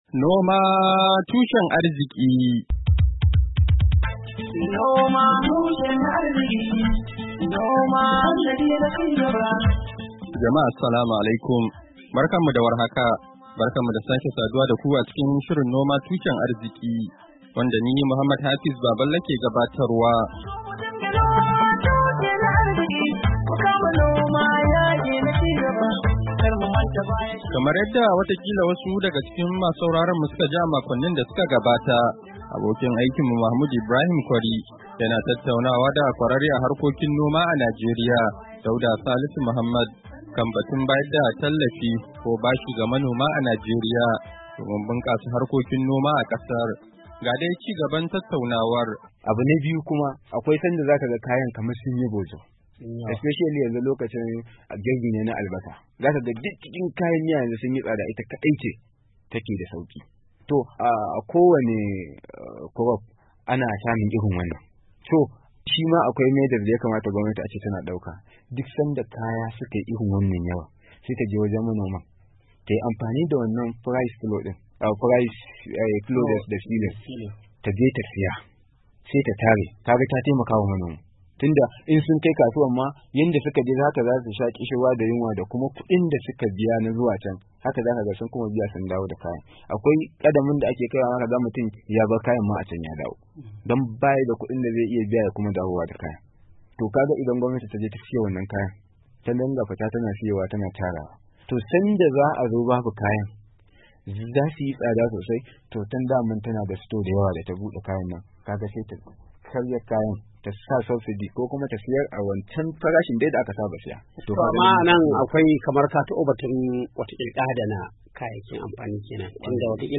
NOMA TUSHEN ARZIKI: Hira Da Kwararre Kan Harkokin Noma A Kan Batun Bunkasa Noma A Najeriya - Kashin Na Biyar - Mayu 16, 2023